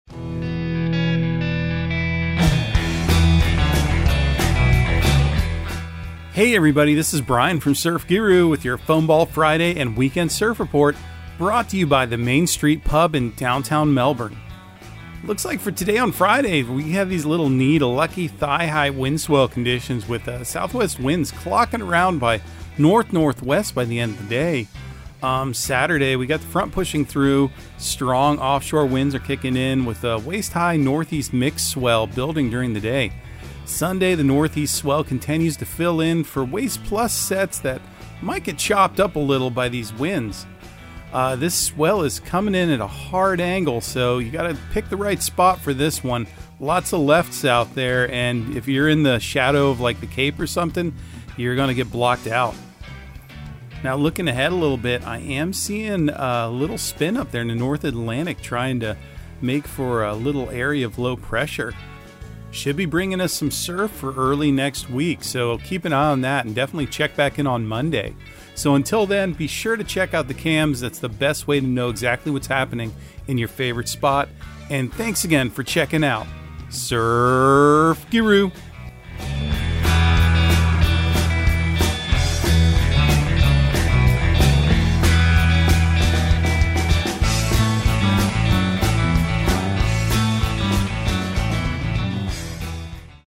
Surf Guru Surf Report and Forecast 01/13/2023 Audio surf report and surf forecast on January 13 for Central Florida and the Southeast.